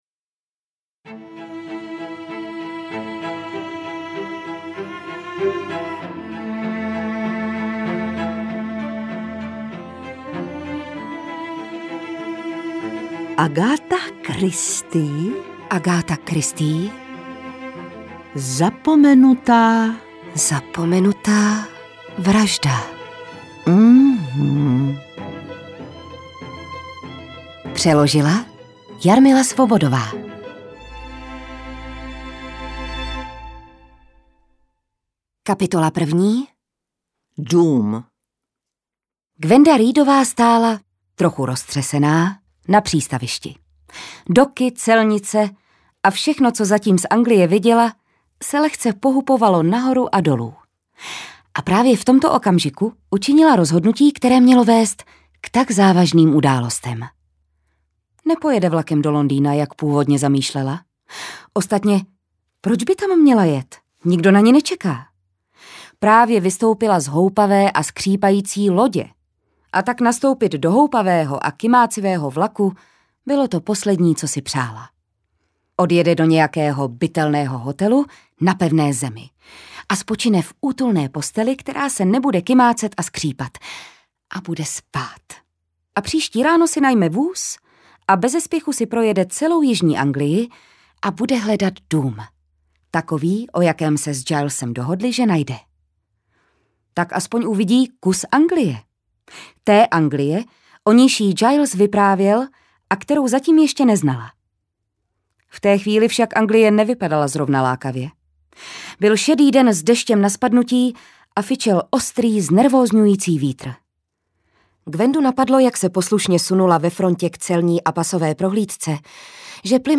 Nejlépe vyniknou přednosti zvoleného zpracování v pasážích, v nichž se díky důmyslné dramatizaci setkají alespoň dva z hereckých představitelů. Hereckou trojici dále doplňuje hudba Daniela Tůmy a Ondřeje Timpla, která rozehrává takřka tajemnou hru klavíru a smyčců. Po většinu času působí zádumčivě až zasněně, čímž dokresluje atmosféru románu.